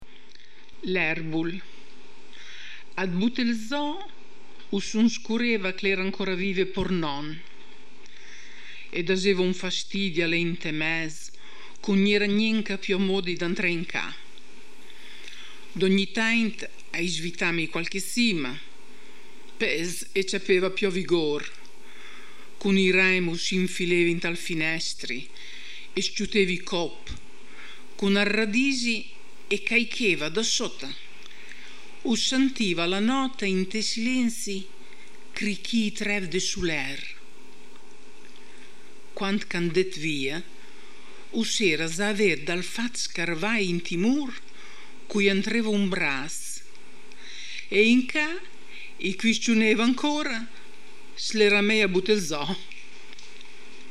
voce recitante